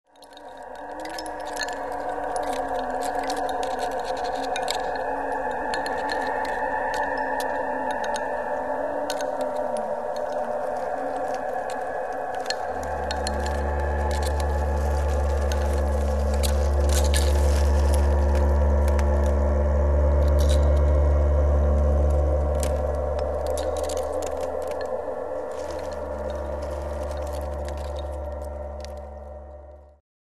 Australian classical music
Australian, Cross-cultural